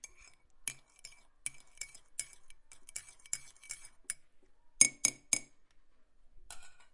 浇灌茶水
描述：把茶倒进茶杯里。使用Zoom H5和XYH5立体声麦克风录制。
Tag: 饮酒 英国 伦敦 饮料 倒水 浇注 伯爵